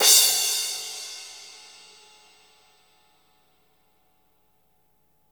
-CRASH 2  -L.wav